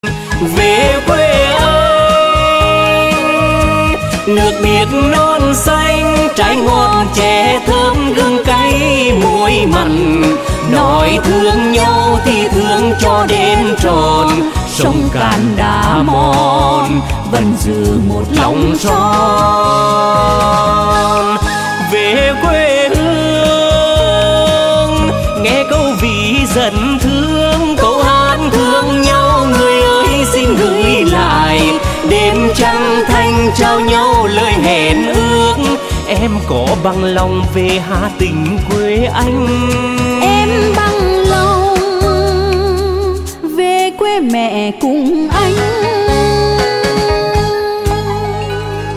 Nhạc Chuông Trữ Tình